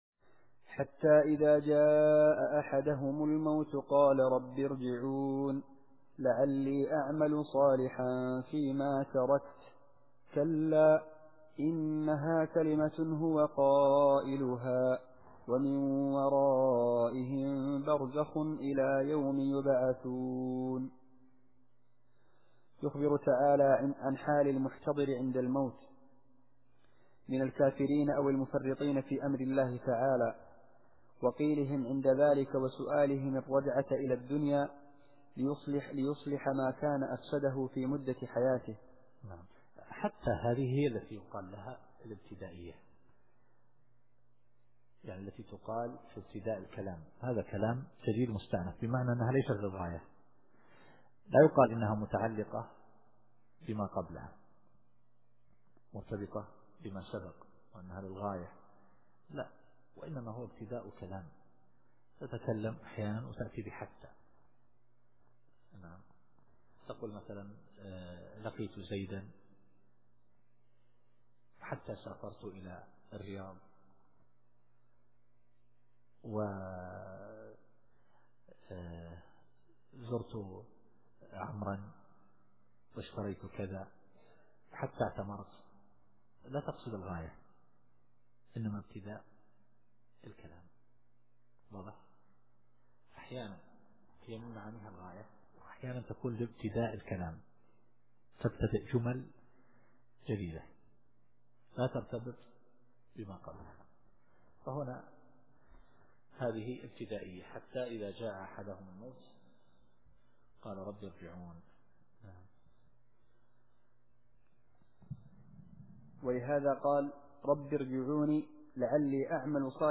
التفسير الصوتي [المؤمنون / 99]